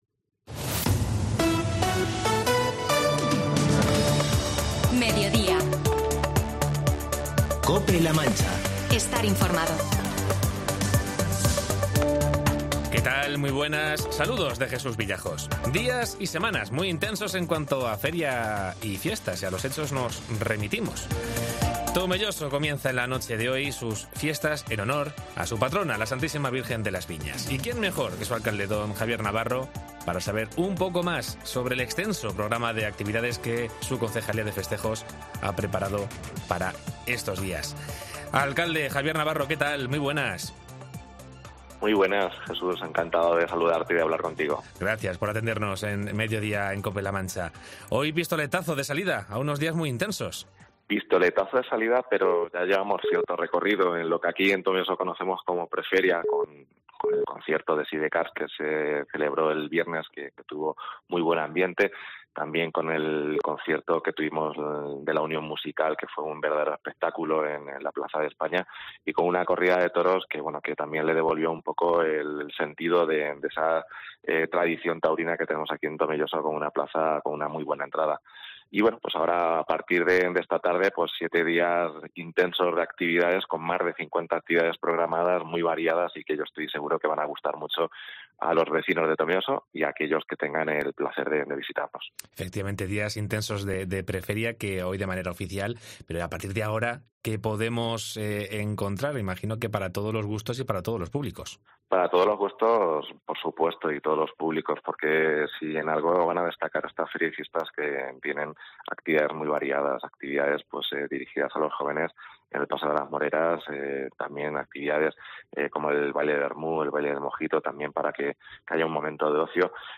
Entrevista a Javier Navarro, alcalde de Tomelloso